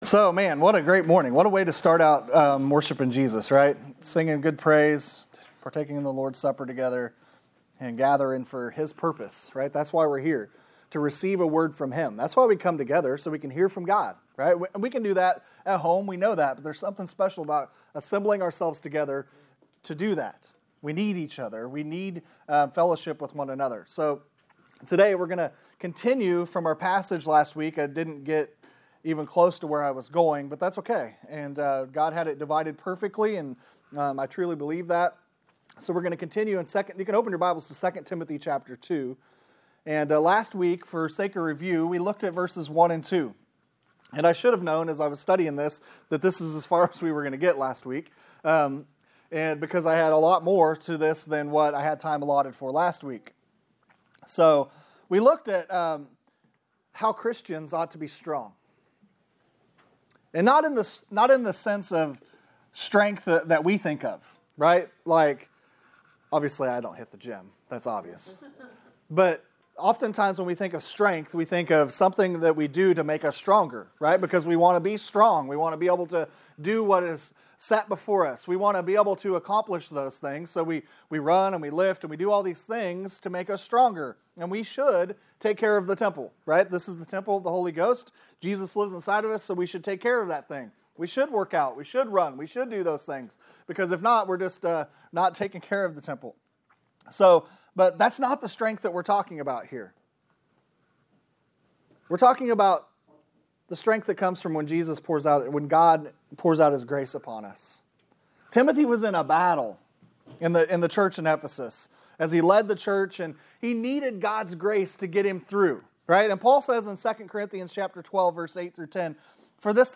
Sermon from June 6